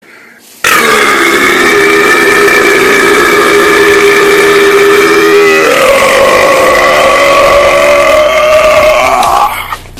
burp.ogg